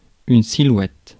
ÄäntäminenFrance : « une silhouette »:
• IPA: [yn si.lwɛt]